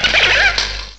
Add all new cries
cry_not_galvantula.aif